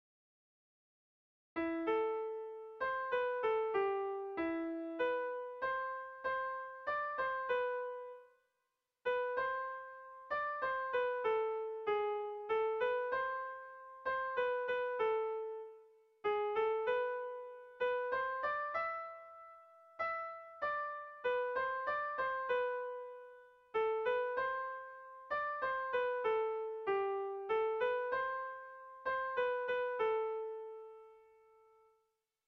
Doinu xarmanta.
Zortziko txikia (hg) / Lau puntuko txikia (ip)